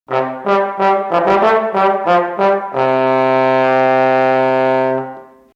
Posaune
posaune.mp3